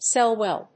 音節cel・lule 発音記号・読み方
/séljuːl(米国英語)/